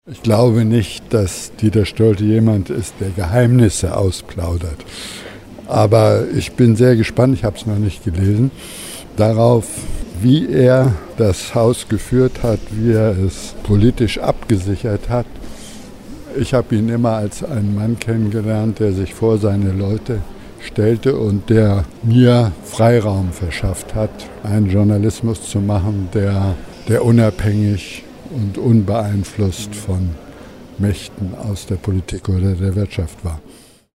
Wer: Klaus Bresser, u. a. ZDF-Chefredakteur (1988-2000)
Was: Statement
Wo: Berlin, ZDF-Hauptstadtstudio